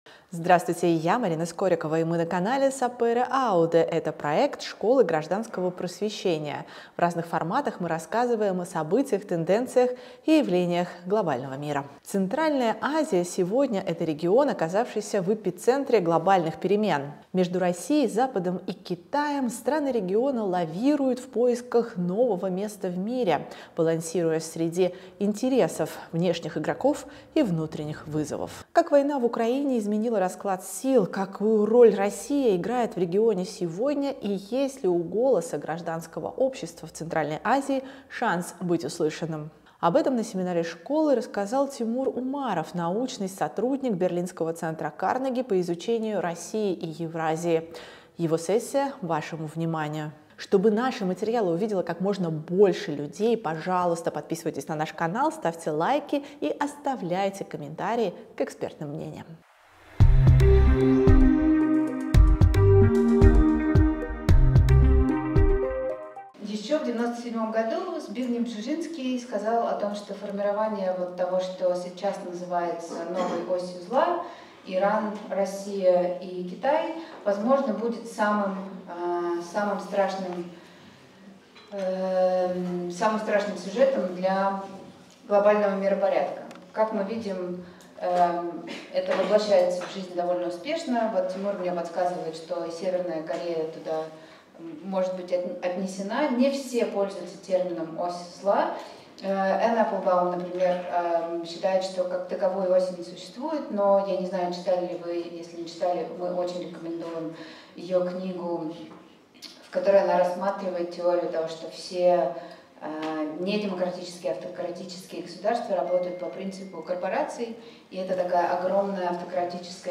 Запись с семинара Школы гражданского просвещения, весна 2025